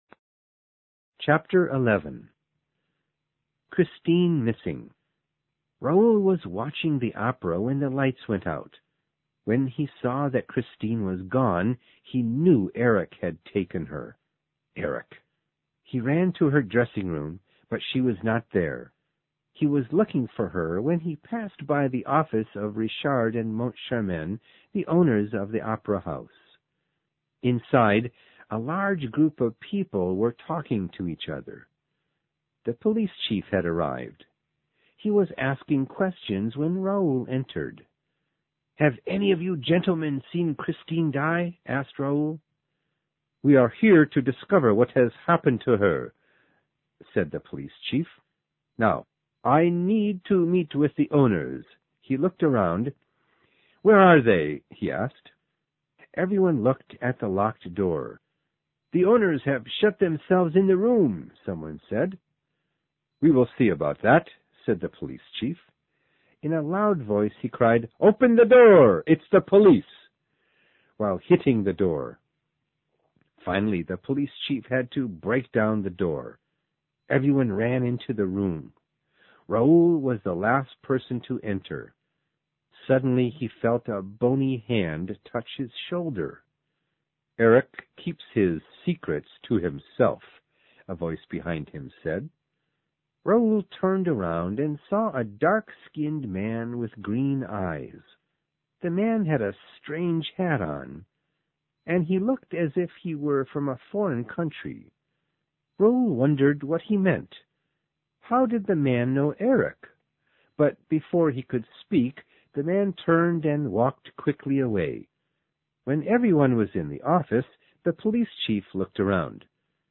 有声名著之歌剧魅影10 听力文件下载—在线英语听力室